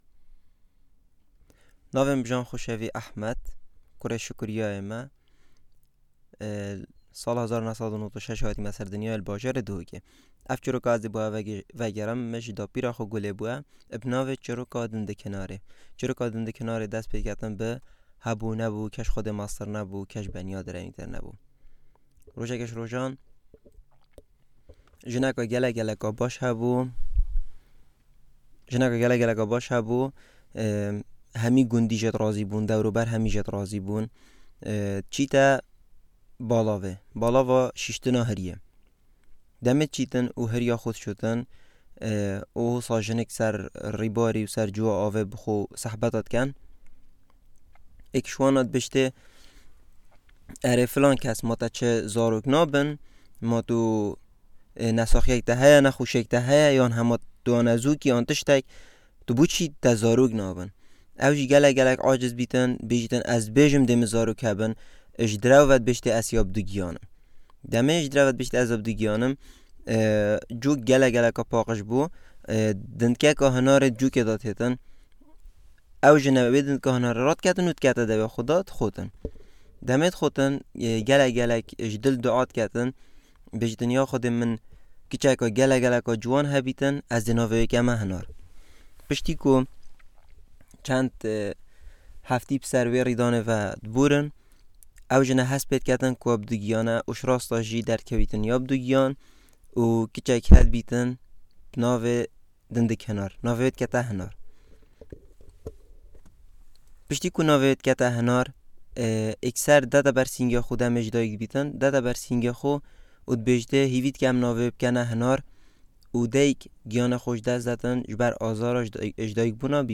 fieldwork